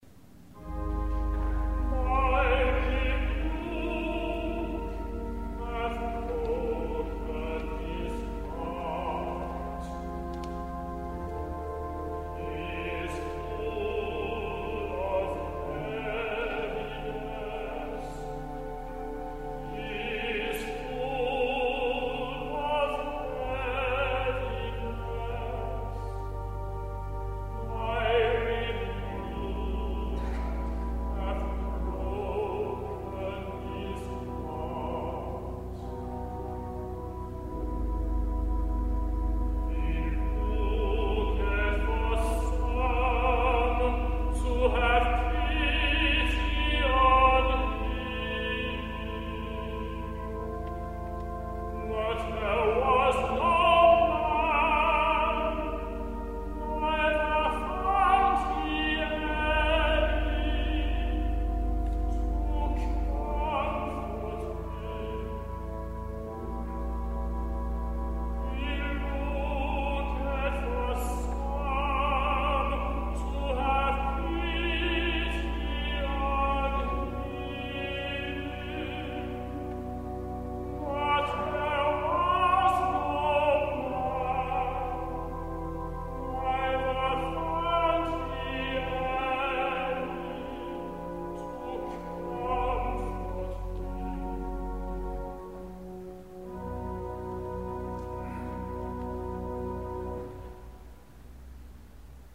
Extracts from live recordings
tenor
organ